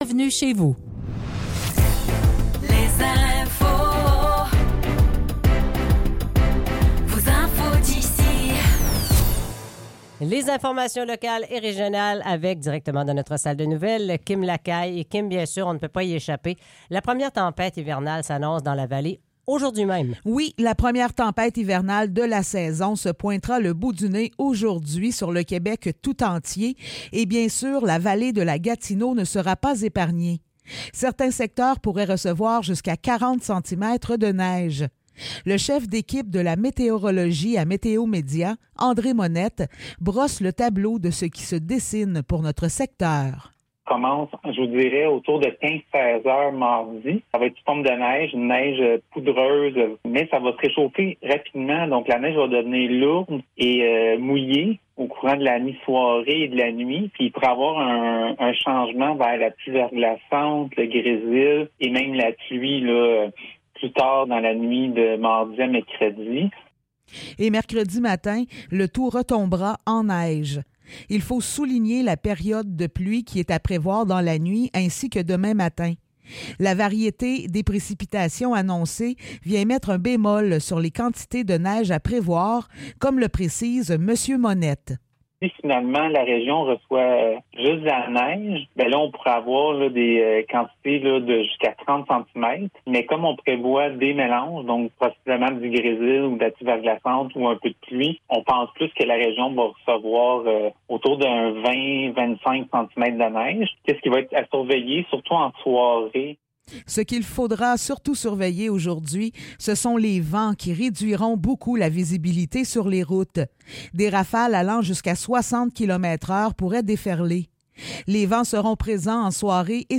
Nouvelles locales - 9 janvier 2024 - 8 h